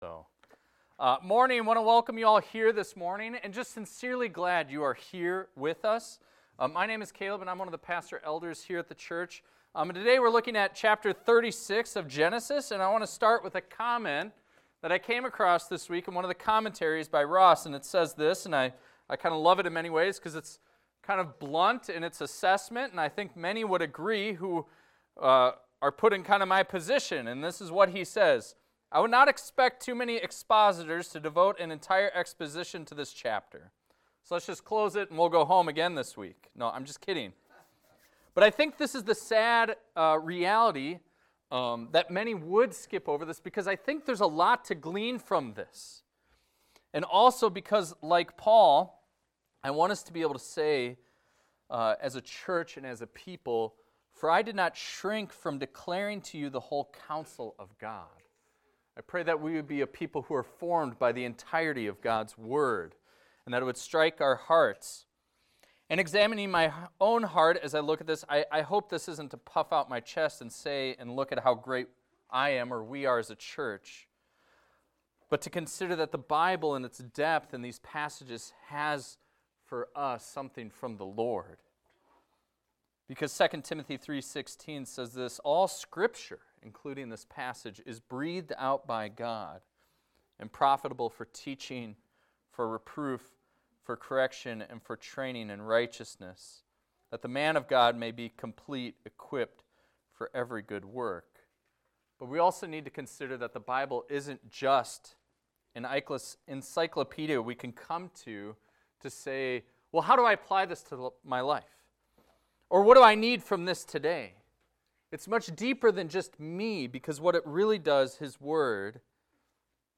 This is a recording of a sermon titled, "Esau the Successful."